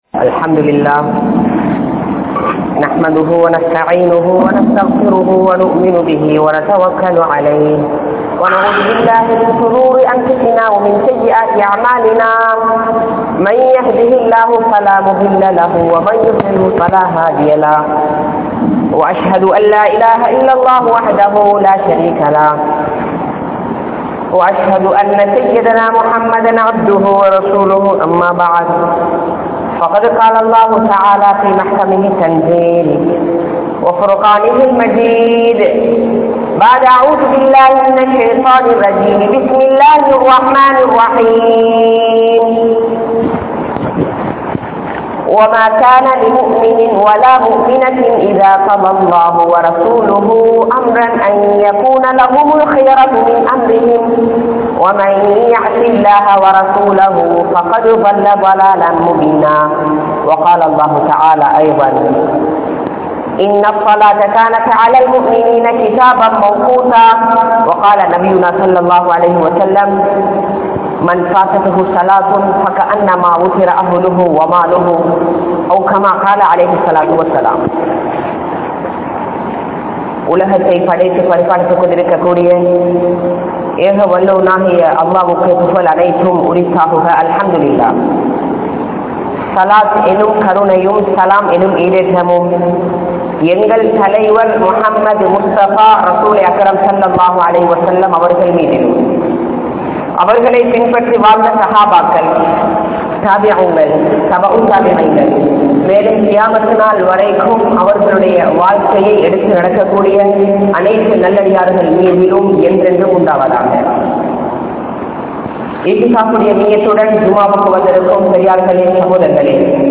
Tholuhaien Avasiyam (தொழுகையின் அவசியம்) | Audio Bayans | All Ceylon Muslim Youth Community | Addalaichenai